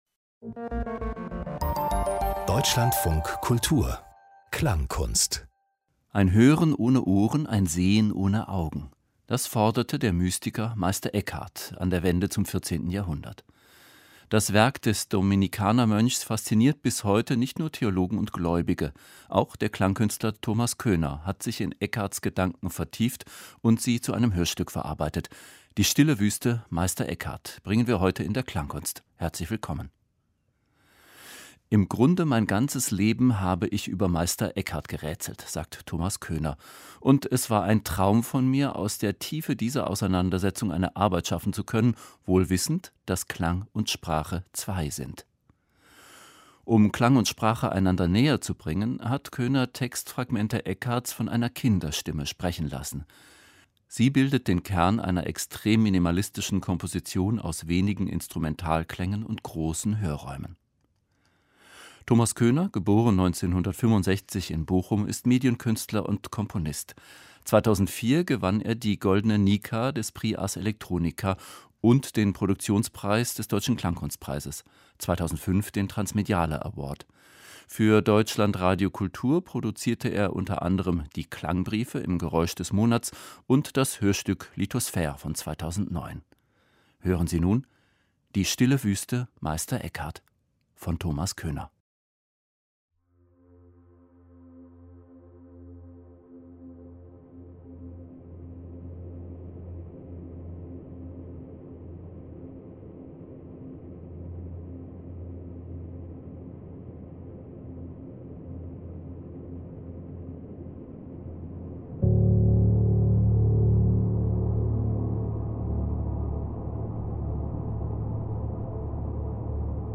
Hörstück über mittelalterliche Mystik - Die stille Wüste - Meister Eckhart
Klangkunst